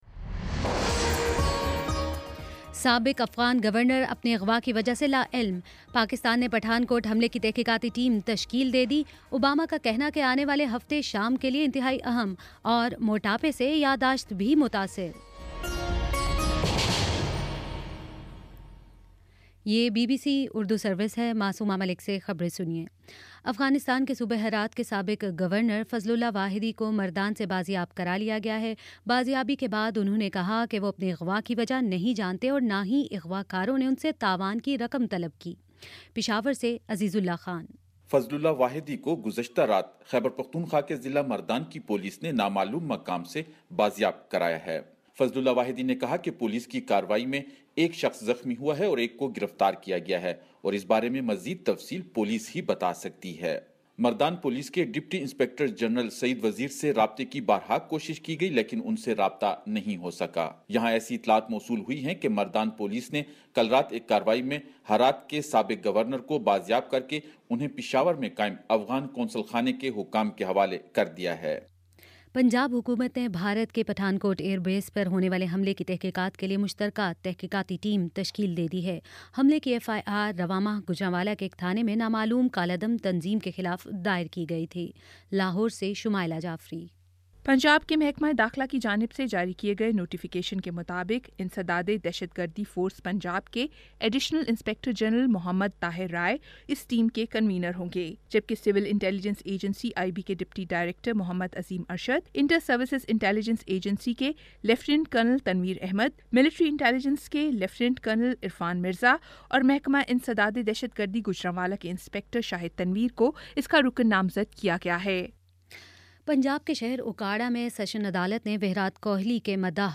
فروری 26 : شام چھ بجے کا نیوز بُلیٹن